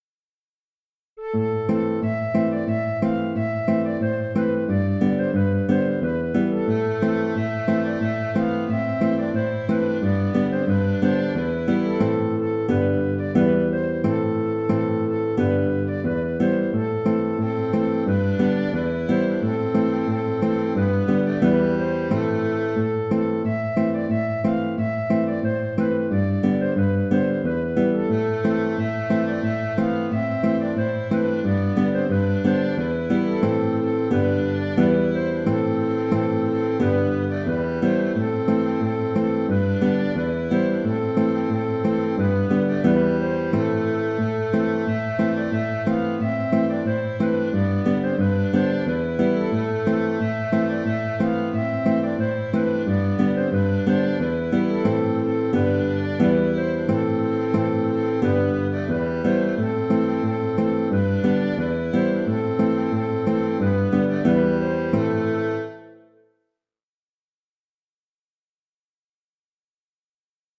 Pelot d’Hennebont (An dro) - Musique bretonne
Cette chanson peut se danser en An dro.
Le morceau est traité ici en La mineur.
< Retour Musique bretonne Pelot d’Hennebont An dro Auteur